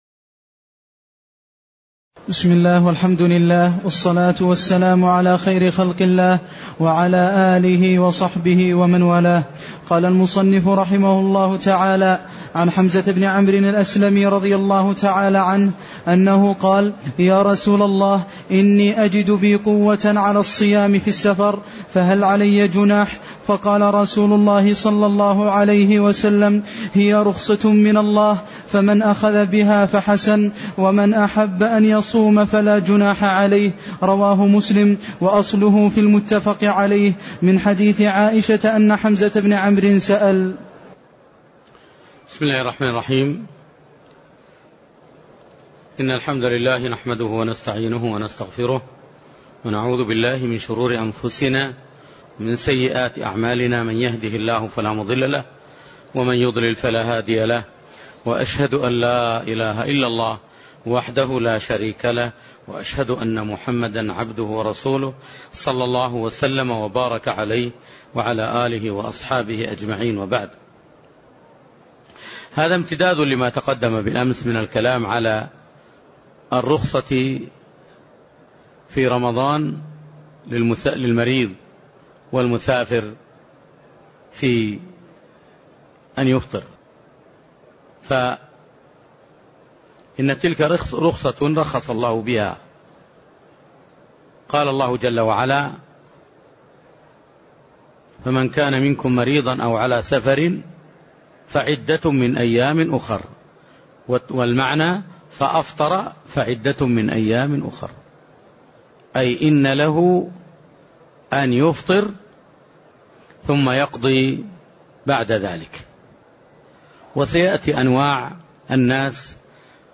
تاريخ النشر ٦ رمضان ١٤٢٨ هـ المكان: المسجد النبوي الشيخ